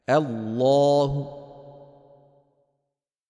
b. Att stanna med Rawm الرَّوْم
Rawm betyder att sänka rösten när man stannar på đammah eller kasrah så att det mesta av deras ljud försvinner, som i: